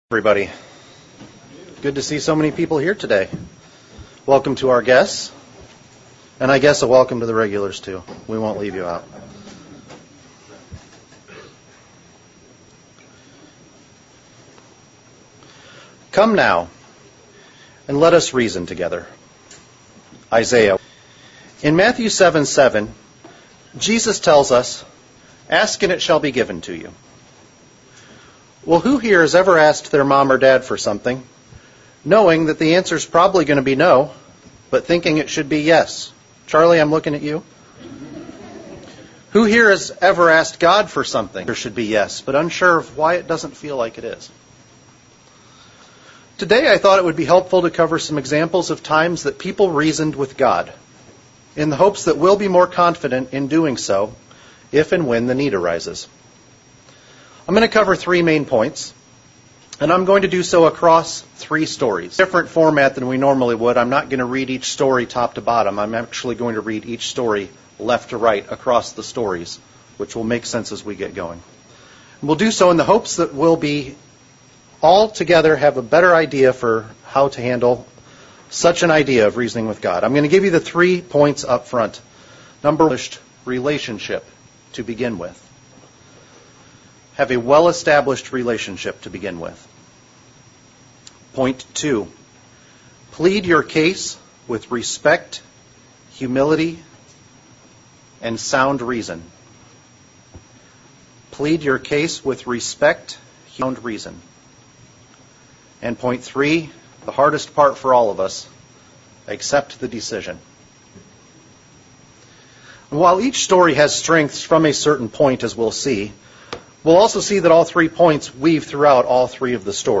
Sermonette looking at how Christ reasoned Scripture with the Pharisees. How well would we do if Christ reasoned withus?